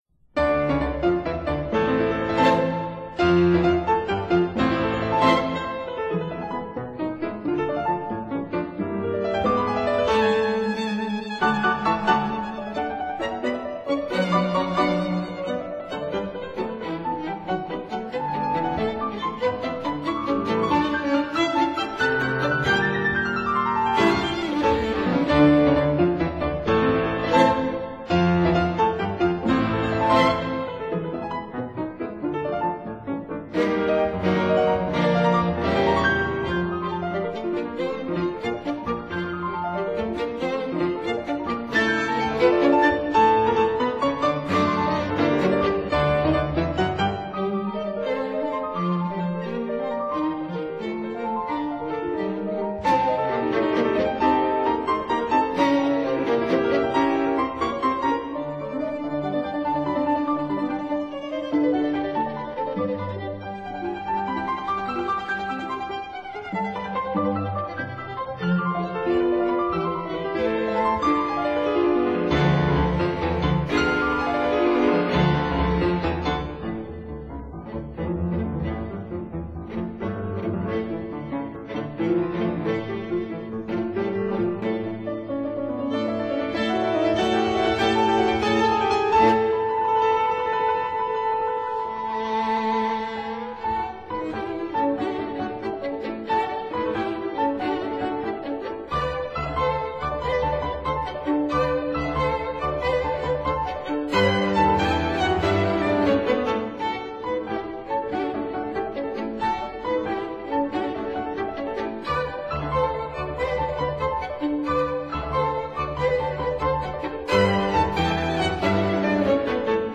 •(01) Piano Trio No. 1 in D minor, Op. 47
•(05) Piano Trio No. 2 in E flat major, Op. 56
•(09) Serenade for violin and piano, Op. 91